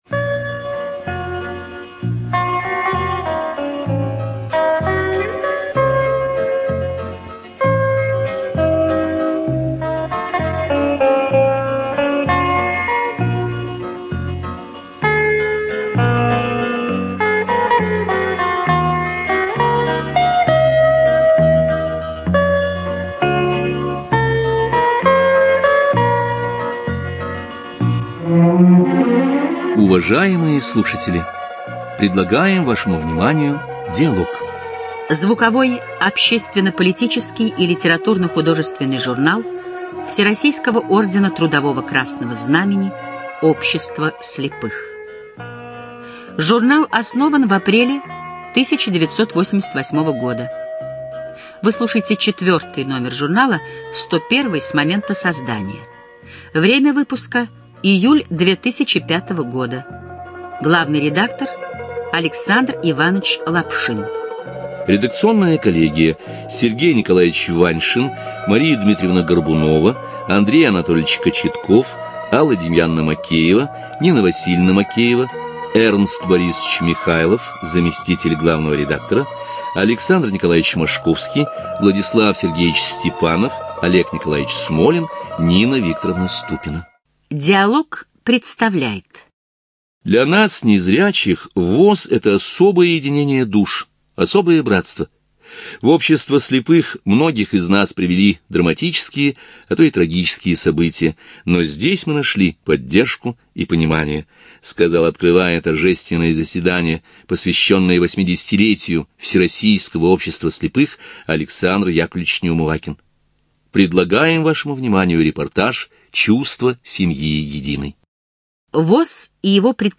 ЖанрРеабилитация, Публицистика, Документальные фонограммы
Студия звукозаписиЛогосвос